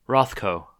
ROTH-koh; Markus Yakovlevich Rothkowitz until 1940; September 25, 1903 – February 25, 1970) was an American abstract painter.
En-us-Rothko.ogg.mp3